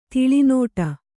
♪ tiḷi nōṭa